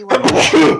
sneeze3.wav